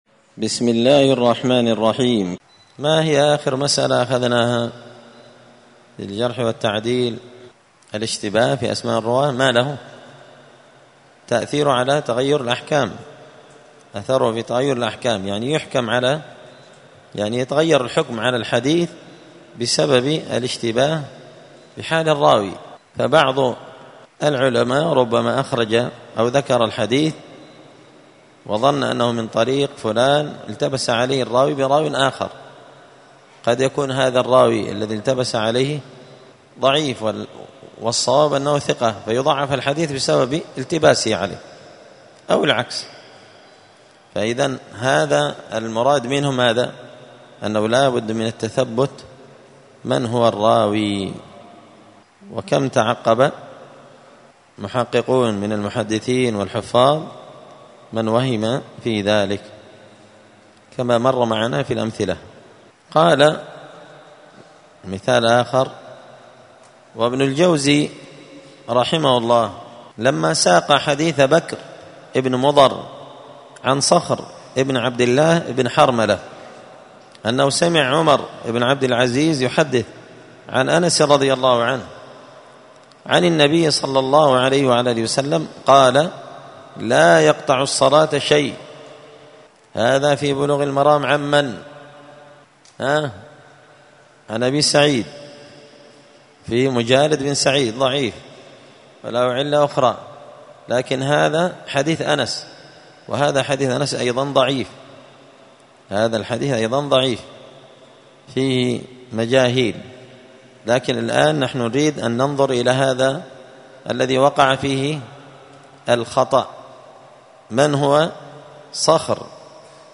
*الدرس الثلاثون (30) تابع لباب الاشتباه في أسماء الرواه وأثره في تغير الأحكام*